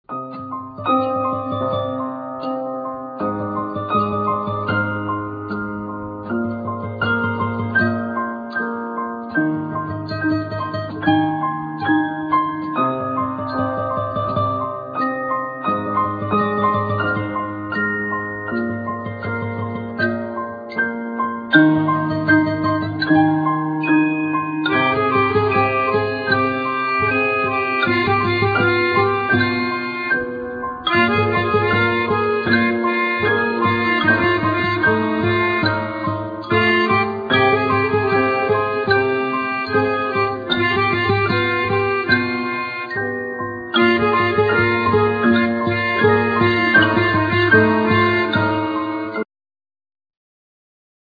Ac.guitar,Organ,Piano,Bass,Melodica,Flute,Xylophne,Recorder